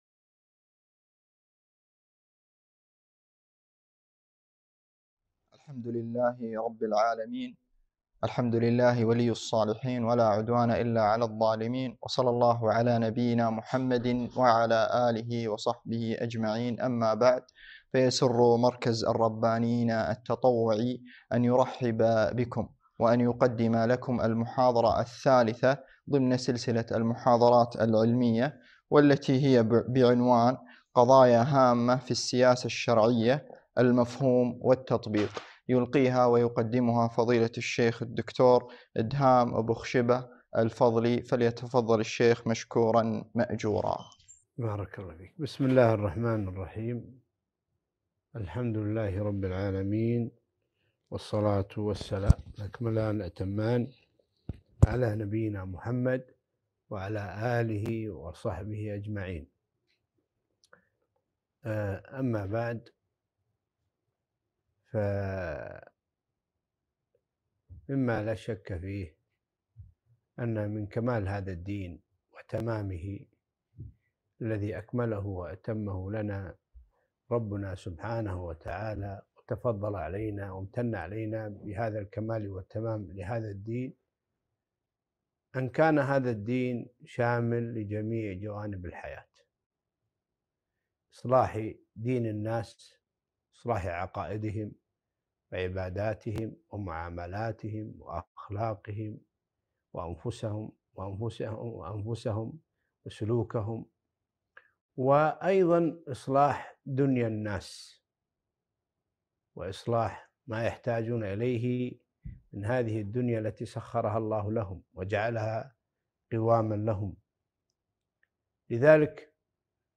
محاضرة - قضايا هامة في السياسة الشرعية المفهوم والتطبيق